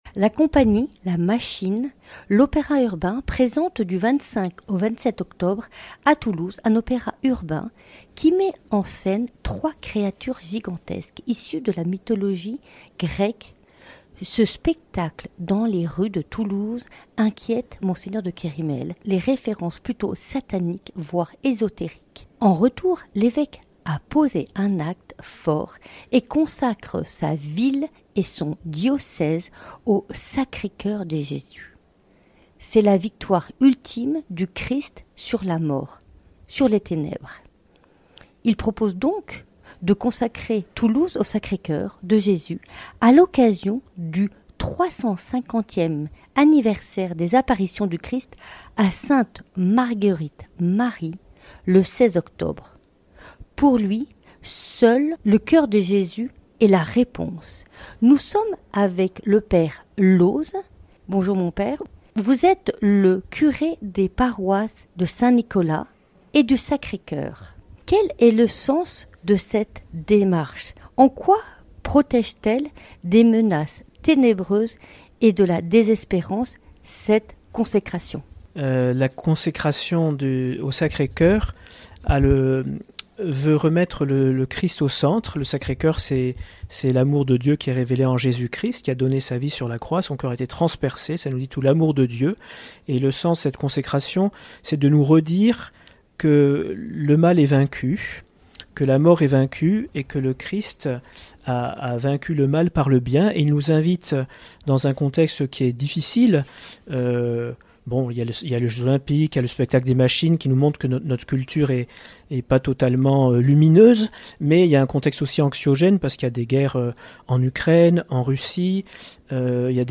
Reportage au cœur de la messe de consécration de la ville et du diocèse de Toulouse